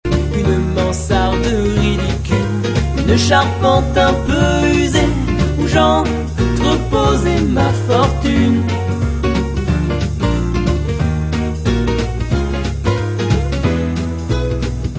chanson influences diverses